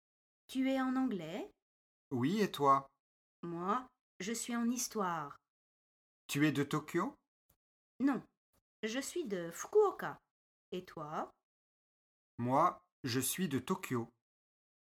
5 dialogues en français (FLE) faciles pour débutant (A1).
Dialogue 4